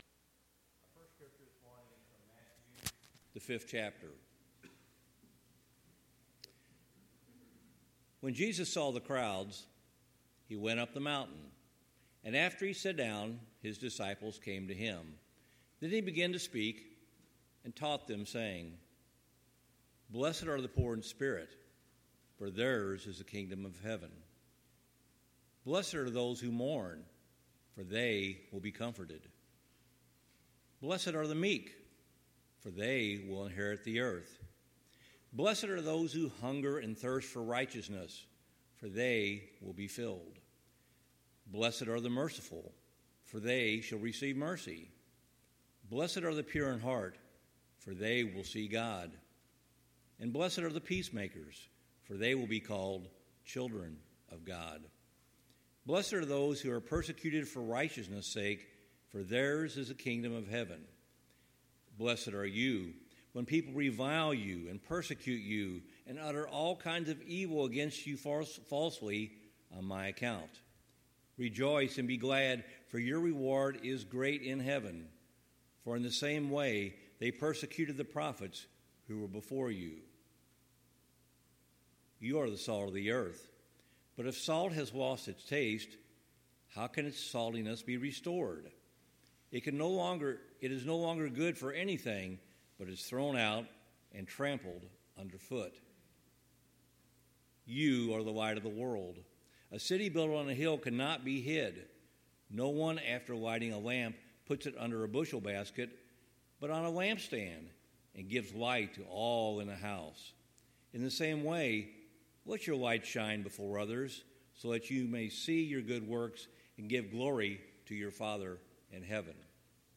Job 9:23-29 Service Type: Sunday Morning Topics